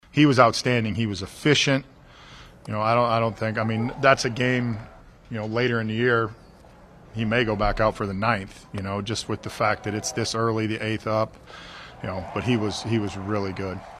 Manager Derek Shelton says Mitch Keller was dominating.